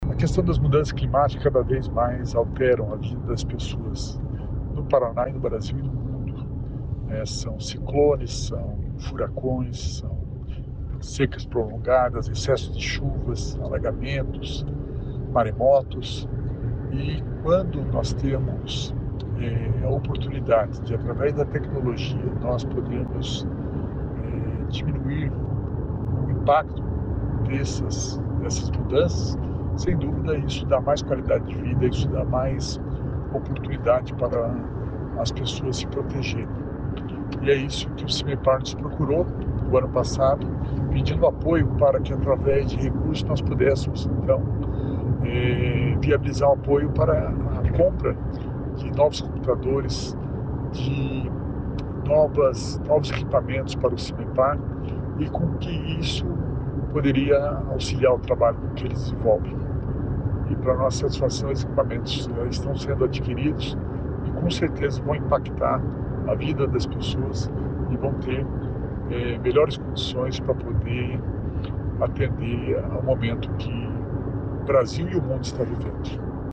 Sonora do secretário da Inovação e Inteligência Artificial, Alex Canziani, sobre a nova tecnologia do Simepar para enfrentar crises climáticas